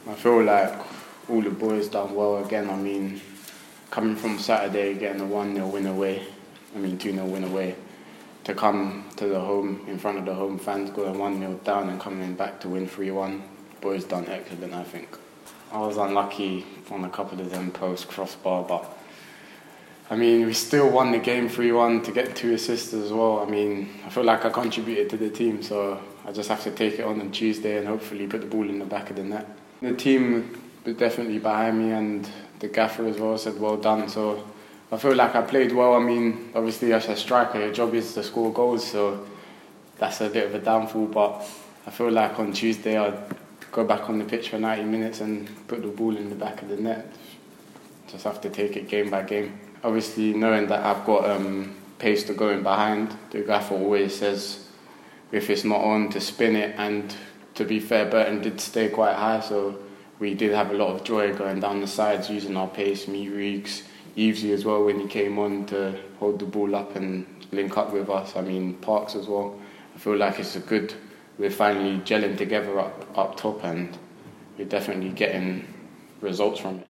speaking after their 3-1 win over Burton Albion